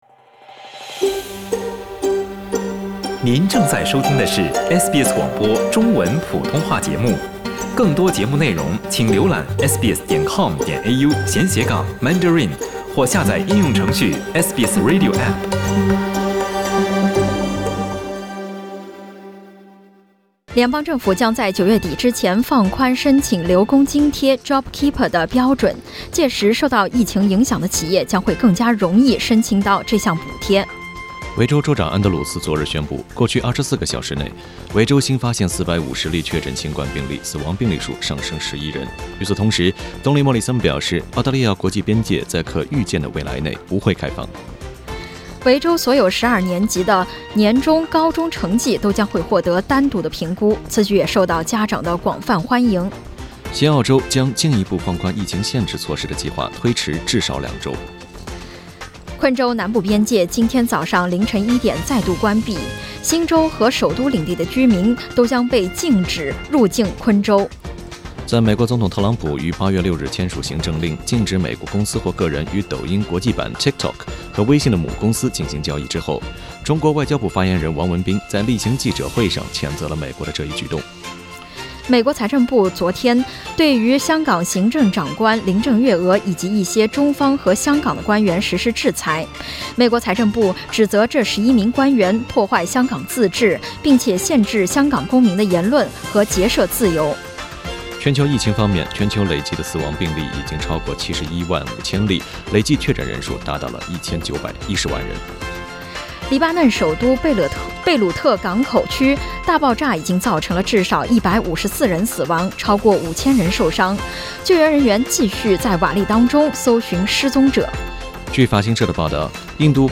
SBS早新闻（8月8日）